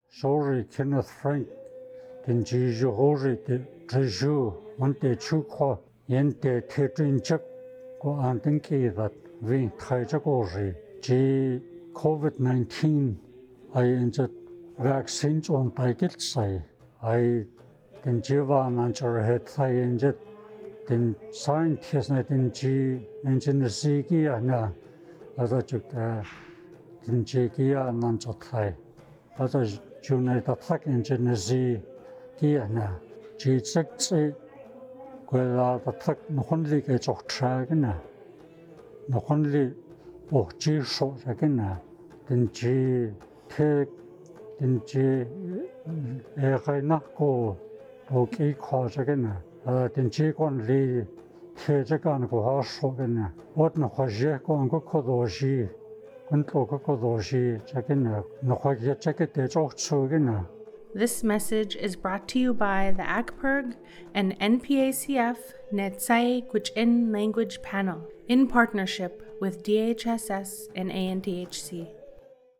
Neets’aii Gwich’in audio PSA (90 seconds)